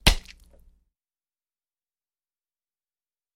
Звук помидора брошенного в лицо